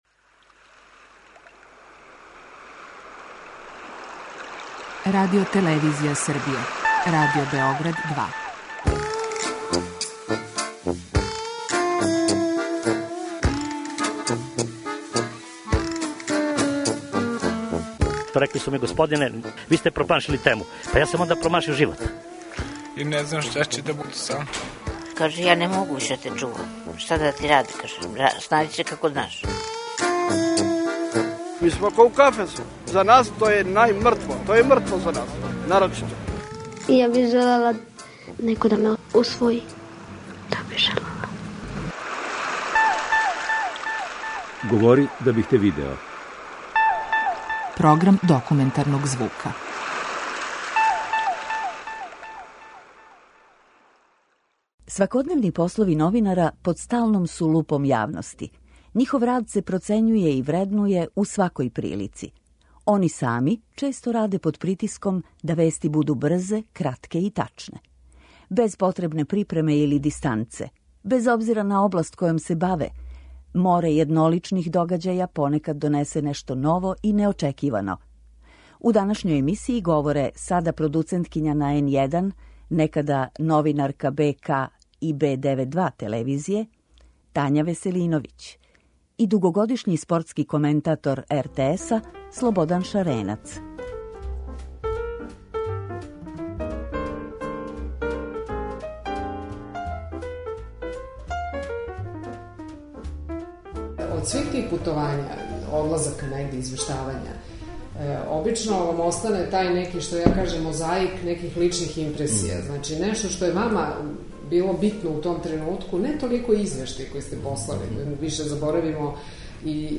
Документарни програм: Бити новинар